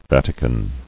[Vat·i·can]